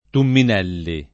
[ tummin $ lli ]